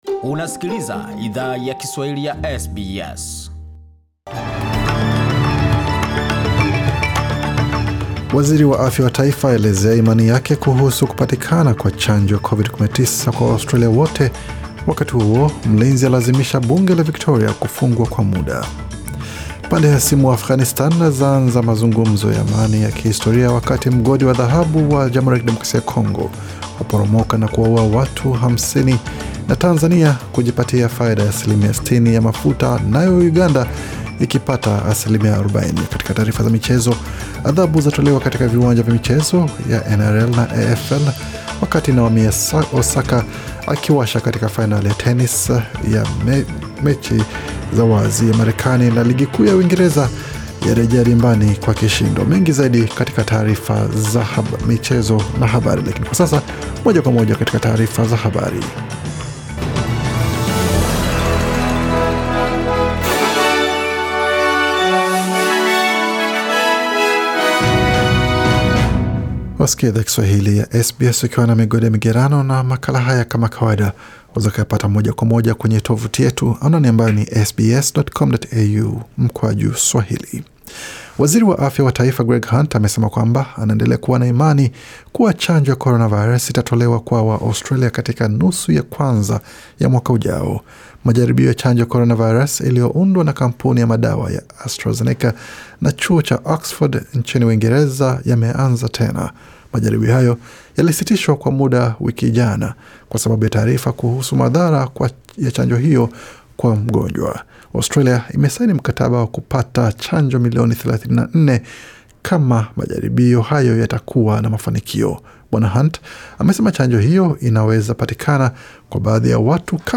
Taarifa ya habari 13 Septemba 2020